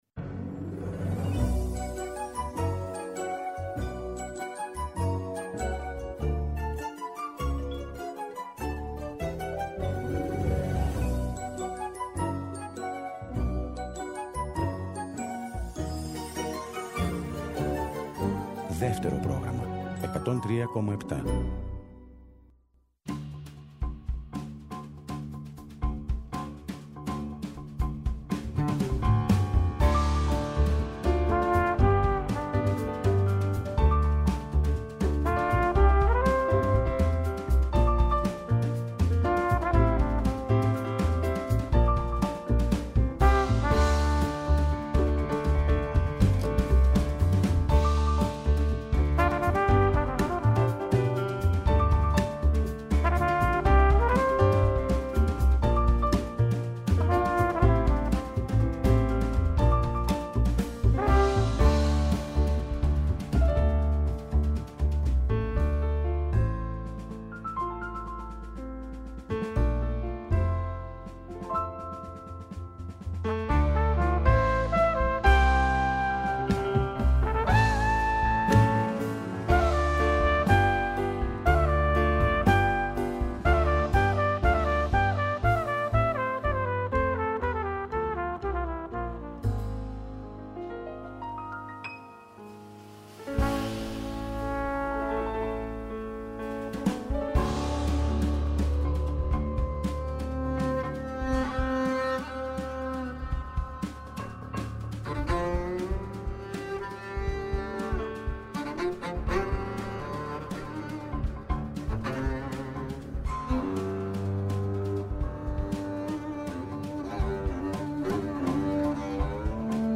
Θα ακουστούν αποκλειστικά από το Δεύτερο Πρόγραμμα 103.7 δύο από τα κομμάτια του νέου δίσκου και θα δοθούν διπλές προσκλήσεις για την παράσταση.
Συνεντεύξεις